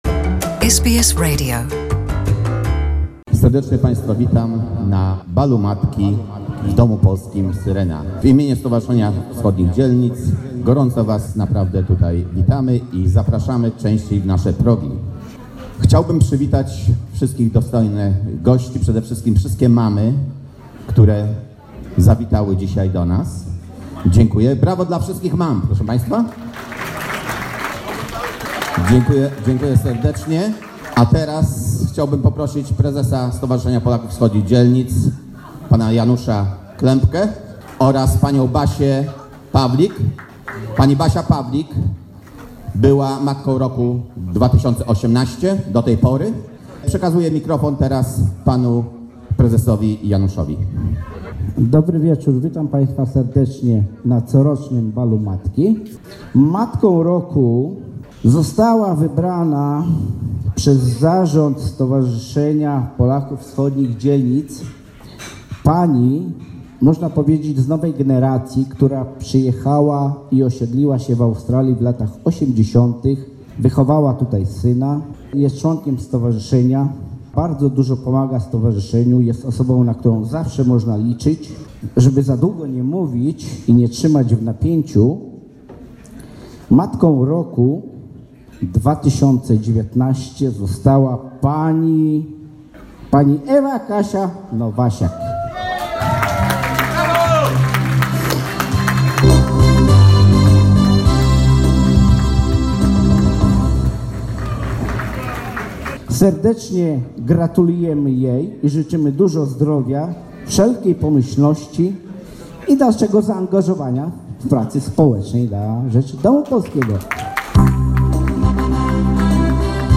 Mother's Day at the Polish House Syrena in Rovwille